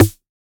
RDM_TapeB_MT40-Snr03.wav